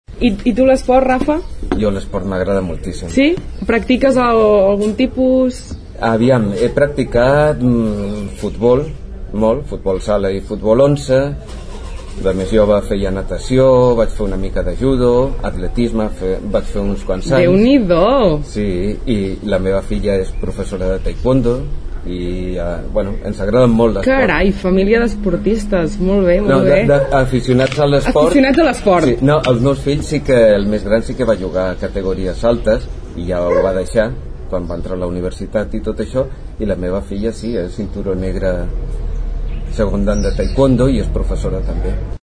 Un format periodístic que combina el reportatge, l’entrevista i la retransmissió, amb la voluntat d’oferir a l’oient una visió més personal del candidat.